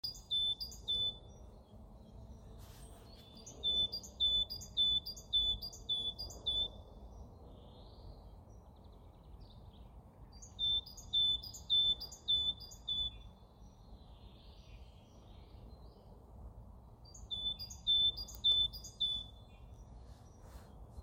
Bird Aves sp., Aves sp.
Administratīvā teritorijaRīga
StatusVoice, calls heard